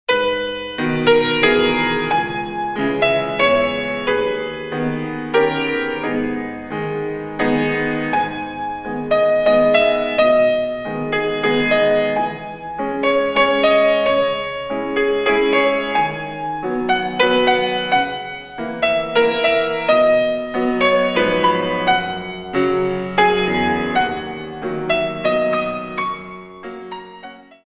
In 3
32 Counts